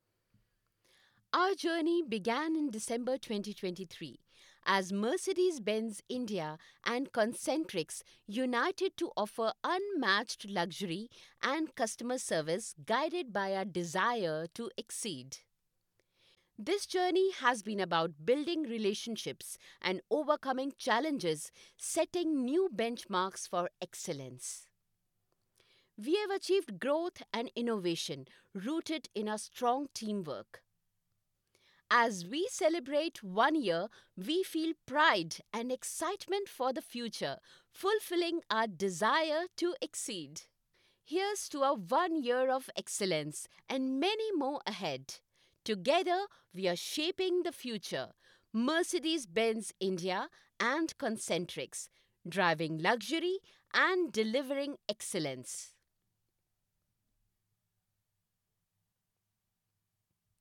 Female
My voice is pleasant , soft and compassionate.
Corporate Narration